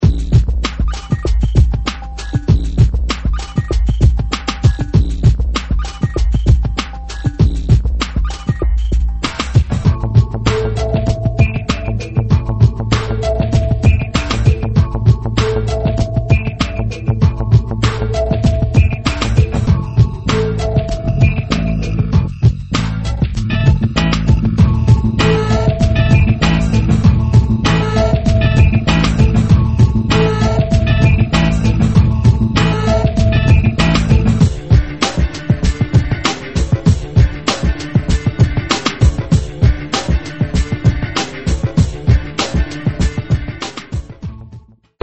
2005 relaxed slow instr.